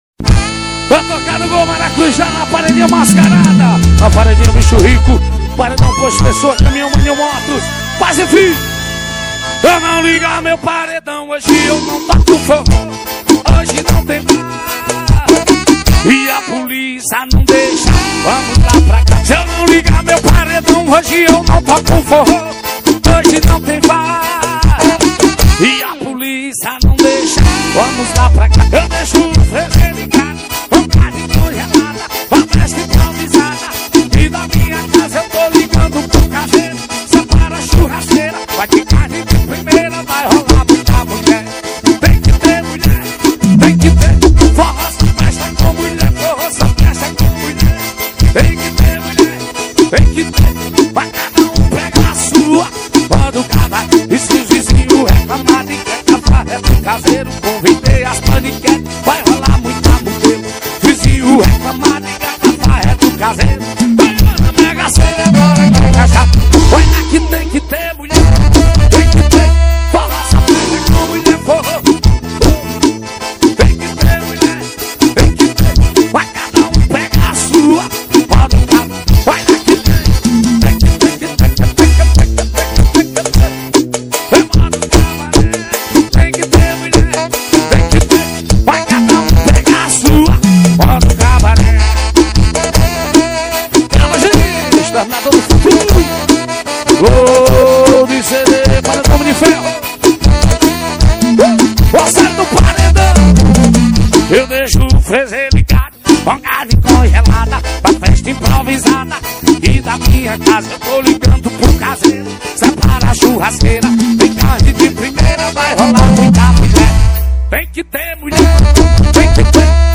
2025-03-07 21:01:50 Gênero: Forró Views